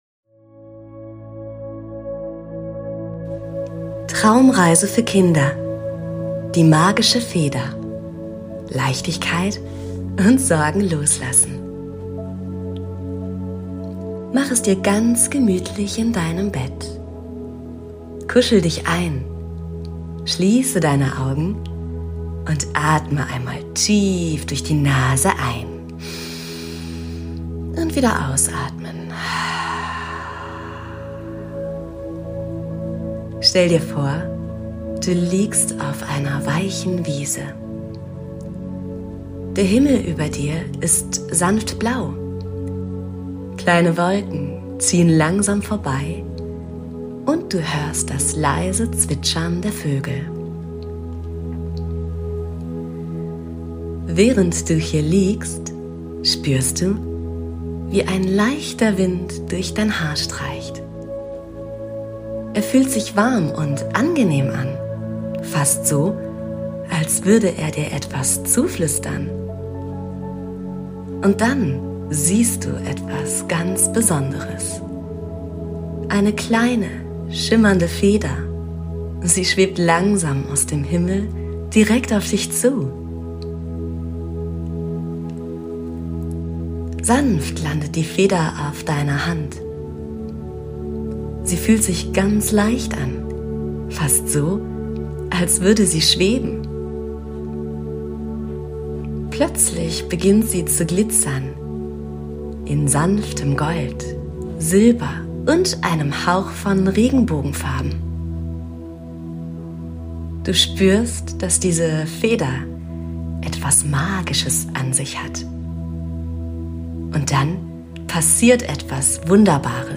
Traumreisen für Kinder